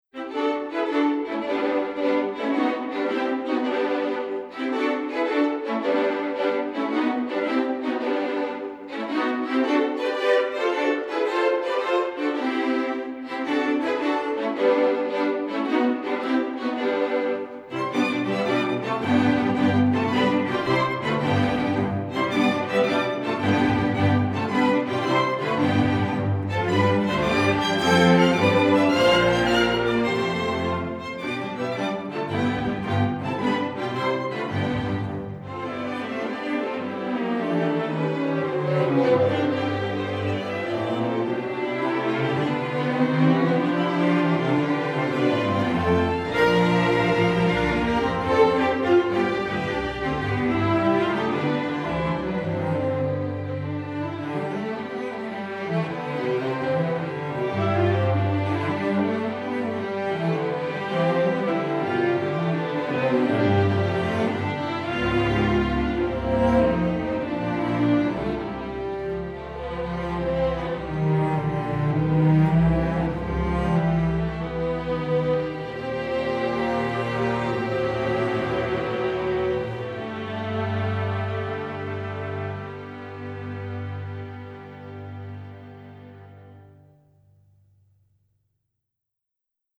String Orchestra (M)   Score